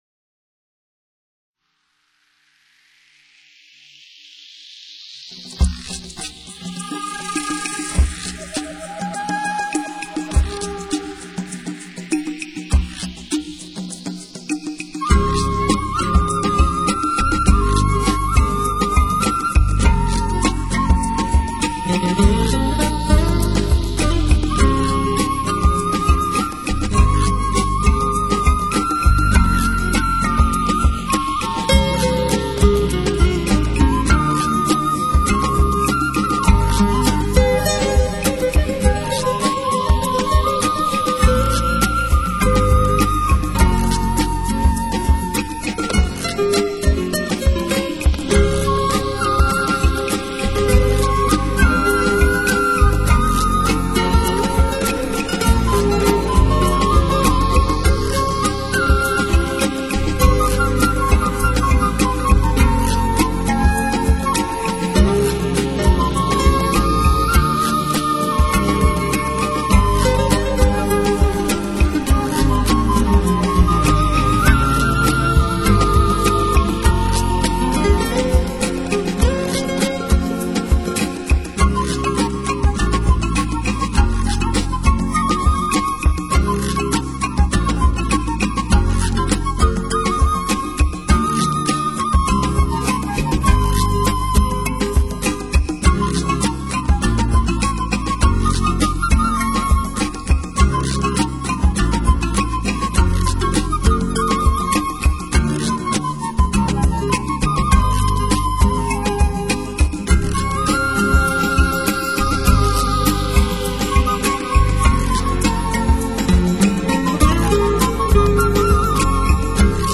新世纪
主奏乐器：长笛 ★ 斯文入世的精致乐风